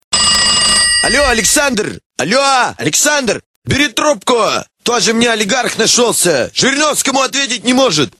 Прикольные рингтоны